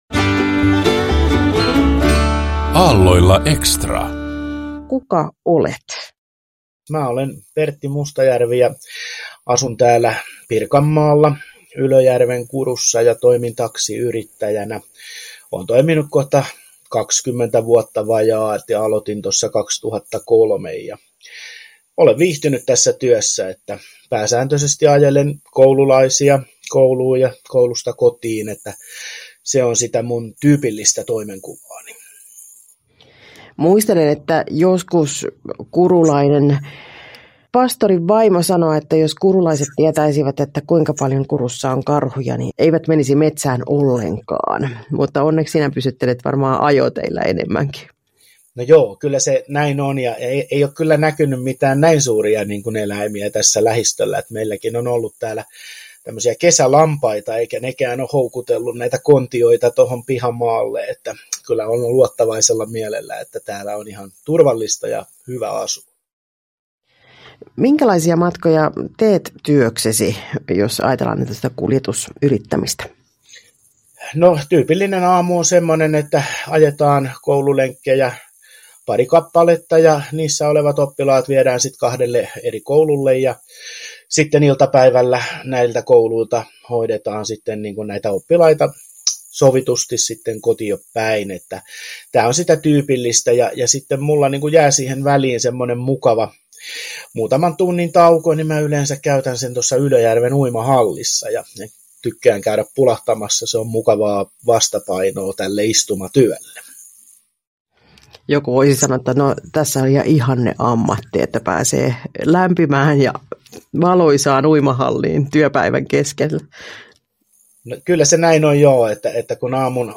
haastattelu (Radio Dei 21.12.2021